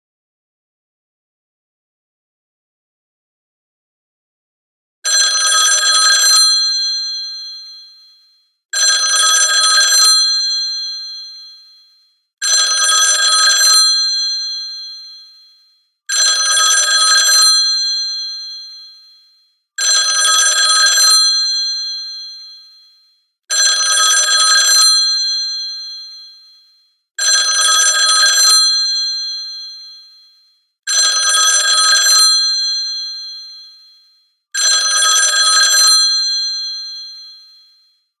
初めの5秒間に無音を追加することによって、着信があった時に最初はバイブレーションだけを感じられるようにしました。